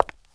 CONCRETE L2.WAV